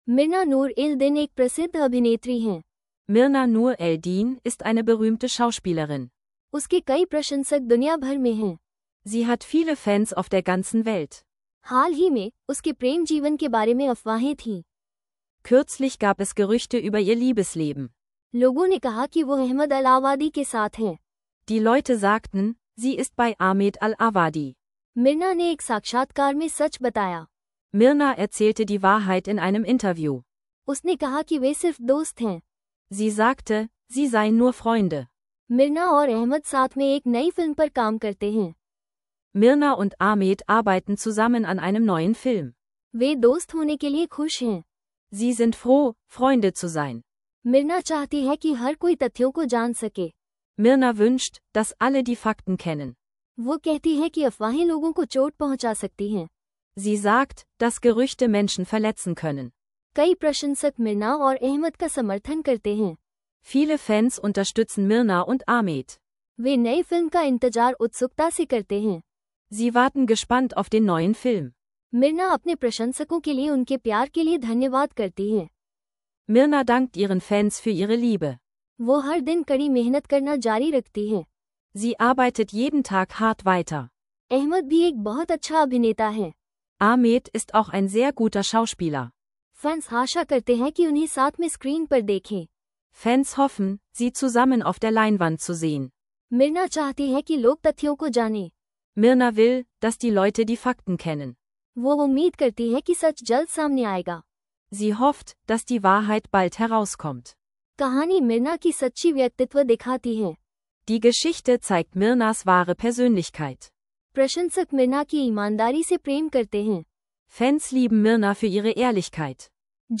In dieser Folge des Hindi lernen Podcasts von SynapseLingo erwarten dich interaktive Hindi Übungen und authentische Vokabeln aus faszinierenden Geschichten. Perfekt für alle, die Hindi lernen unterwegs oder zuhause mit KI-unterstütztem Audio Sprachkurs effizient verbessern möchten.